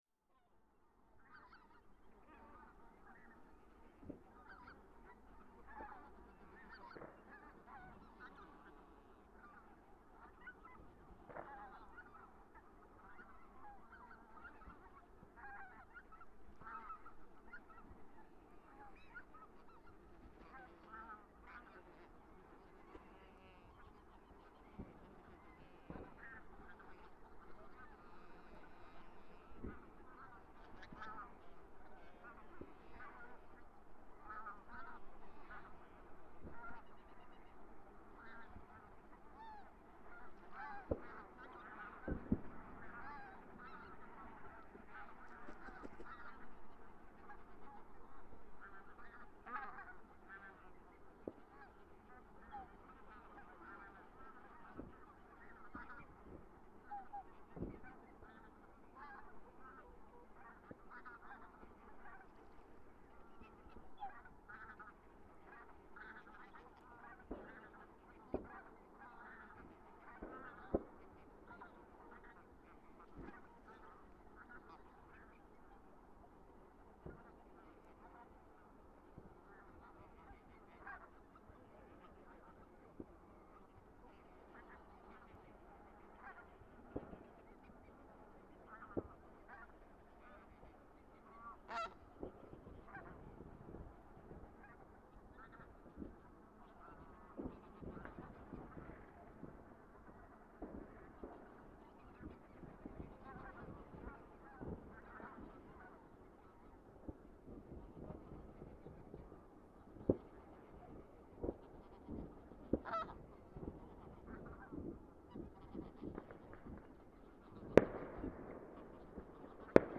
At 11pm I started the proper recording session.
Simultaneously to the fireworks increasing intensity the waterfowl´s anxiety
The first flocks flew around an screamed in the darkness.
Fortunately the fireworks stopped after half an hour, maybe depending on the
PFR08714, 111231, new years eve 2011/12, Flachwasserzone Mannhausen, Germany